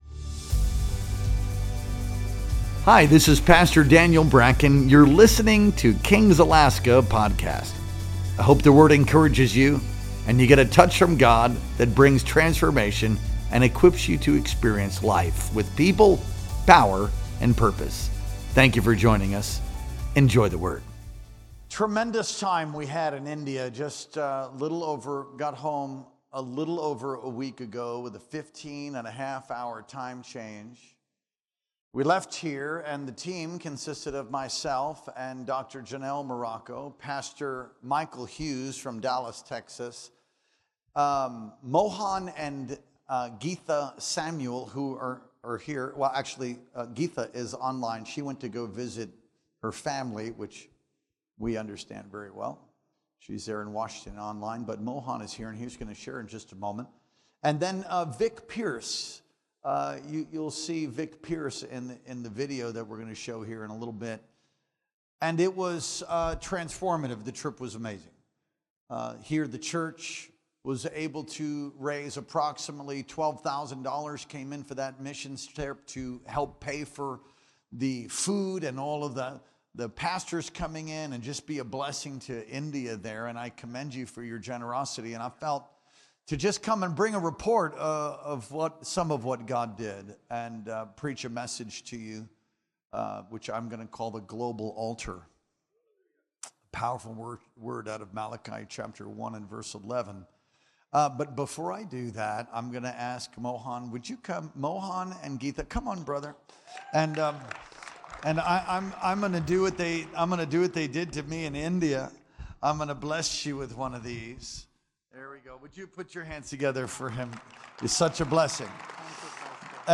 Our Sunday Night Worship Experience streamed live on March 30th, 2025.